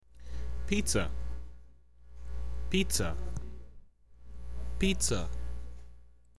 Please listen and see if you can imrpove your pronunciation for the word pizza.